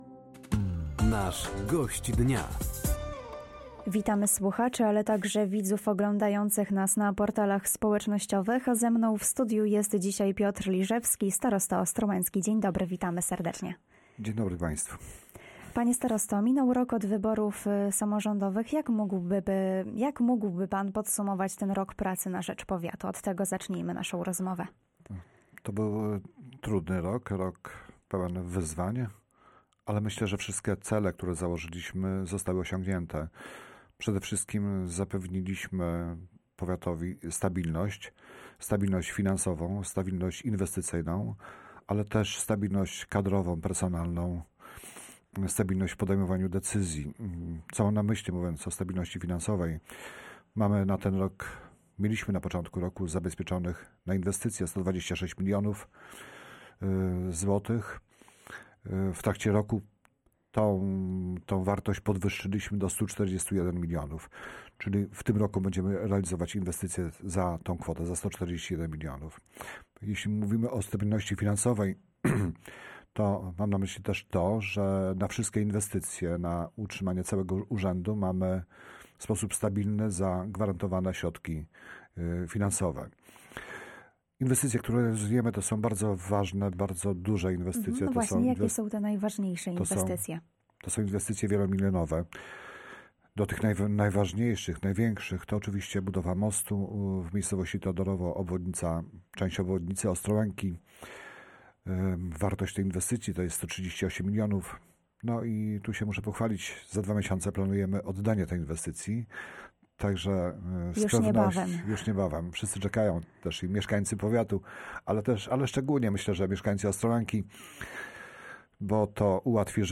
O sprawach ważnych dla mieszkańców regionu mówił Piotr Liżewski, starosta ostrołęcki.